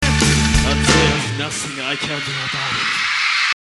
That's pretty clear, too - I guess his connection to K from Gravitation was already being born...;)